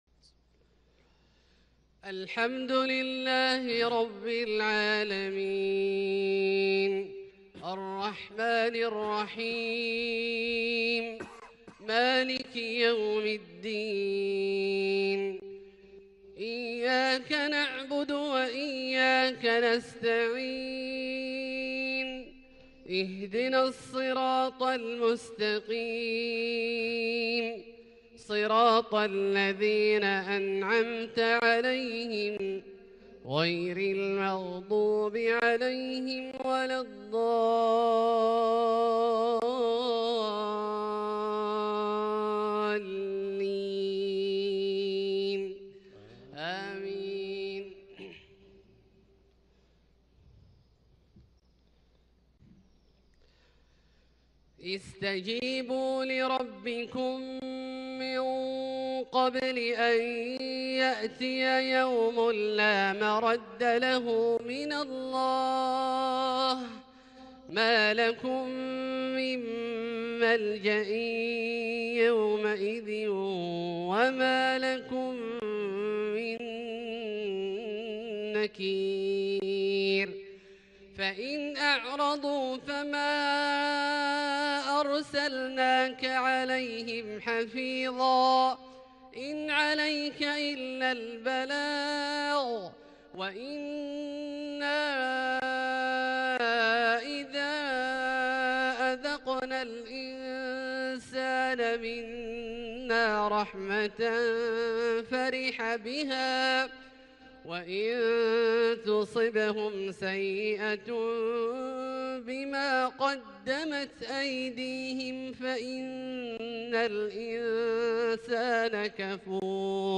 تلاوة روحانية جميلة من اواخر الشورى {47-53}مغرب ٧-٧-١٤٤٢هـ > ١٤٤٢ هـ > الفروض - تلاوات عبدالله الجهني